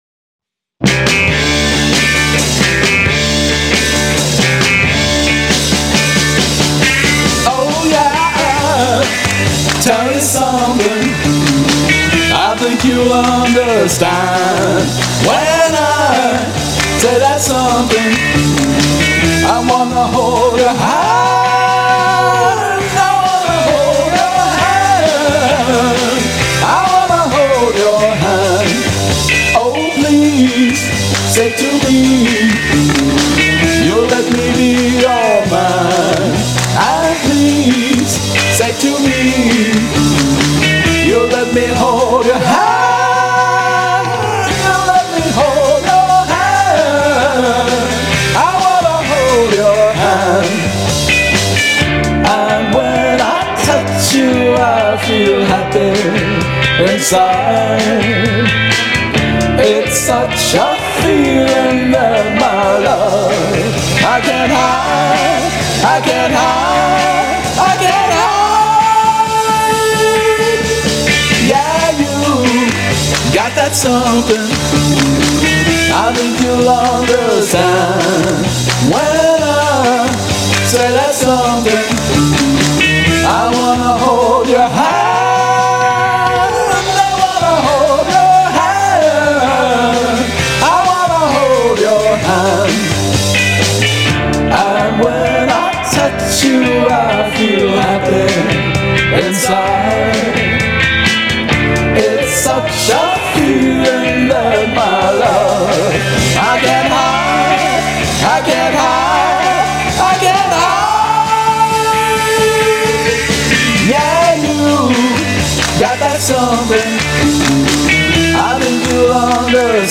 My cover of one of my all-time favorite songs!